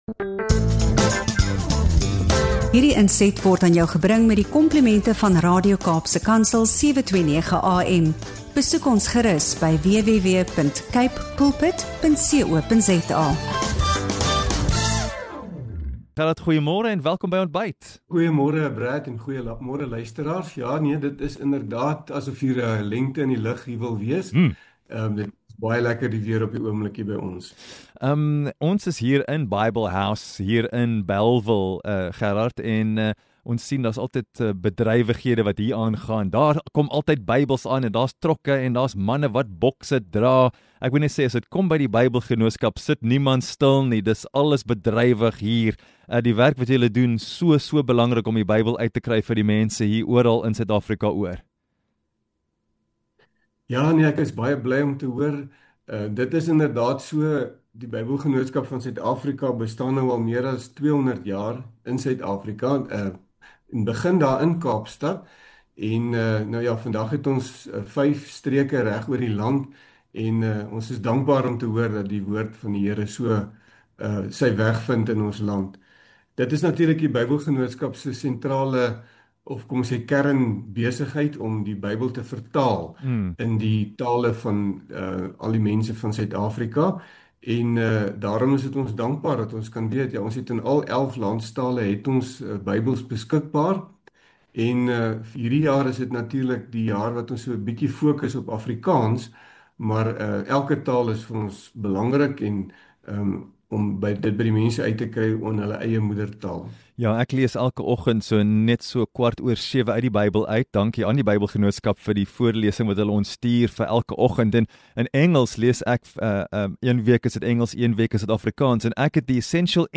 In hierdie inspirerende gesprek